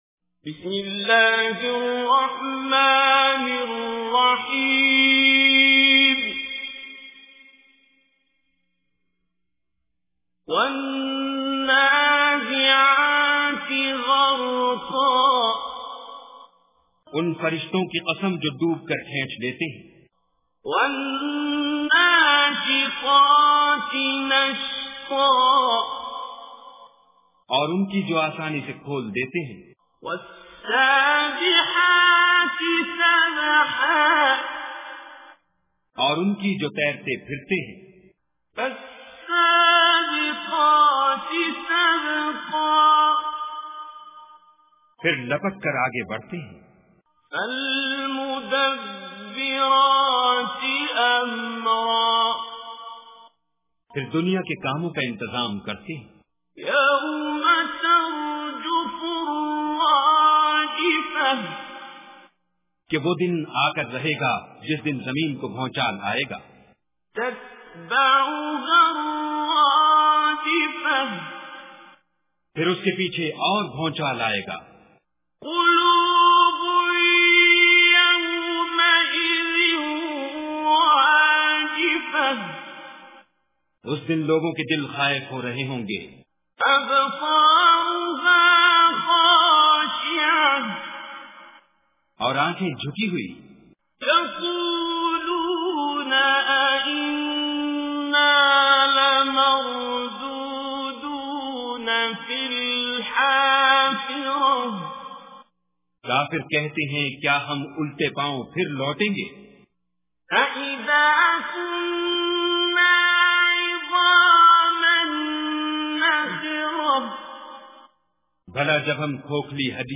Surah Naziat Recitation with Urdu Translation
Surah Naziat is 79 Surah or chapter of Holy Quran. Listen online and download mp3 tilawat / Recitation of Surah Naziat in the beautiful voice of Qari Abdul Basit As Samad.